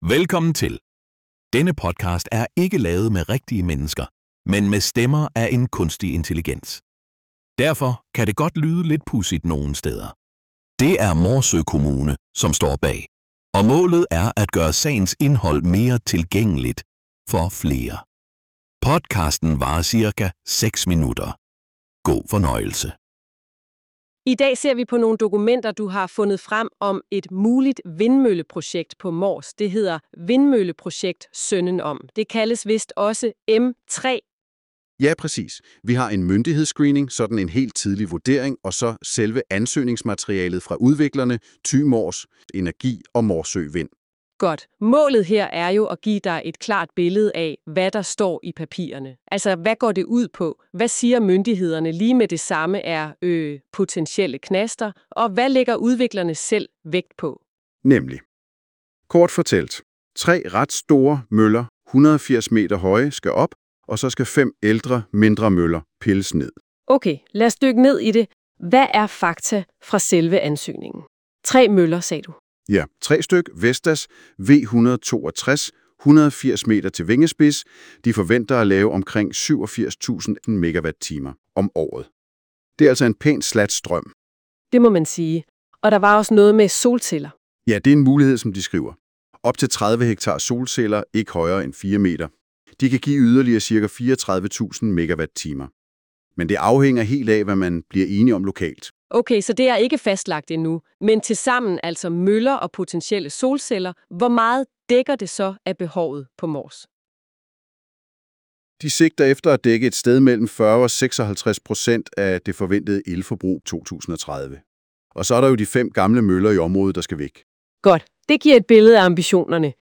Podcasten er ikke lavet med rigtige mennesker, men med stemmer af en kunstig intelligens. Derfor kan det godt lyde lidt pudsigt nogen steder.